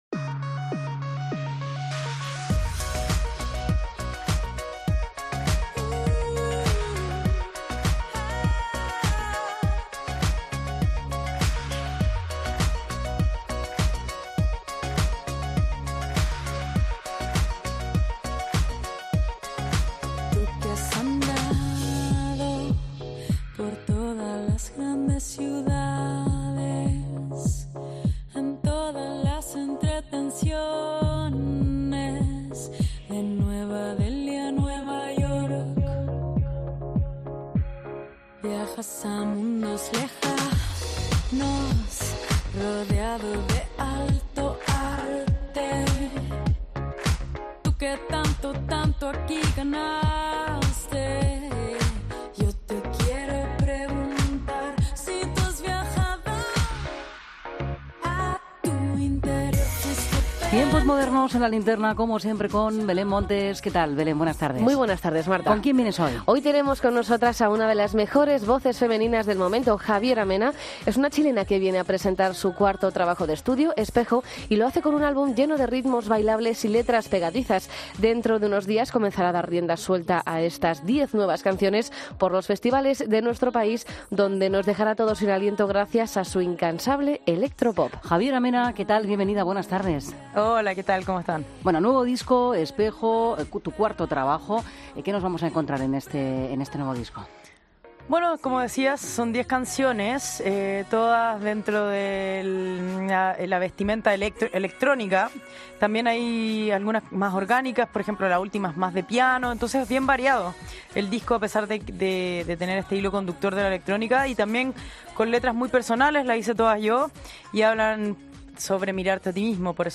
Entrevista a Javiera Mena en La Linterna